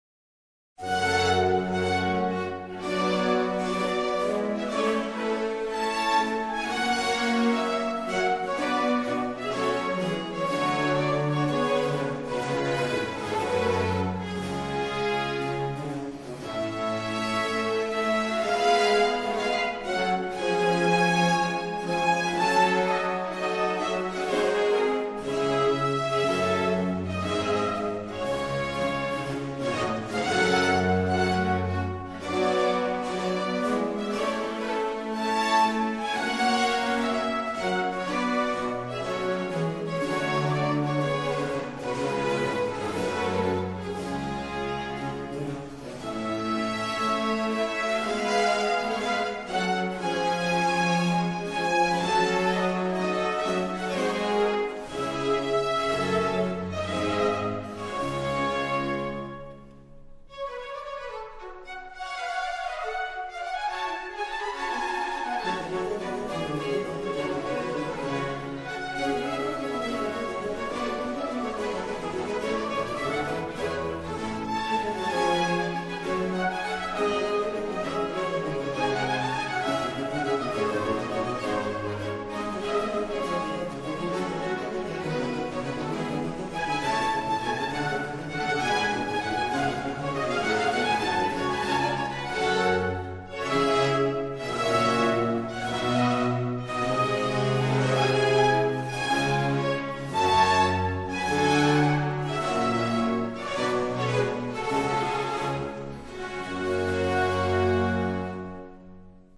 Música antiga
A3-analisi-auditiva-antiga-audio-24-06.mp3